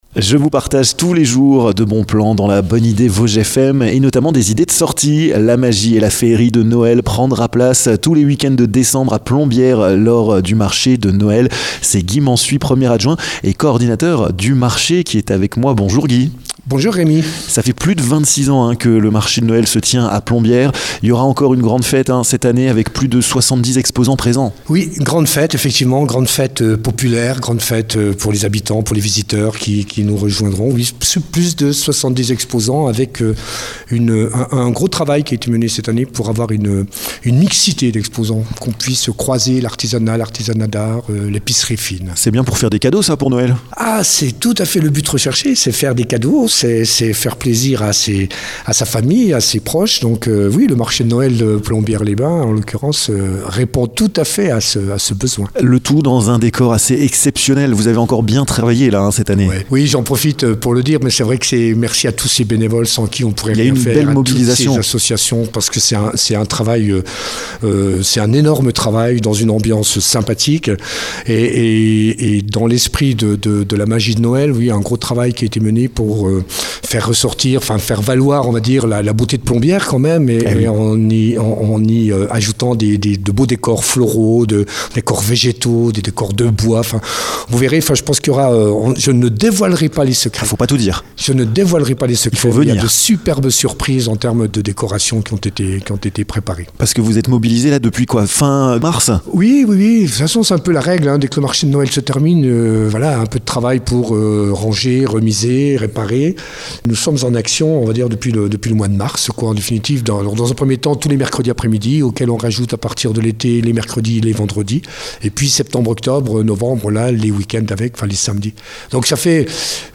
Guy Mansuy, 1er adjoint de la ville de Plombières, vous présente ce marché de Noël qui existe depuis 26 ans! Un rendez-vous à ne pas louper pour cette nouvelle édition rassemblant plus de 70 exposants!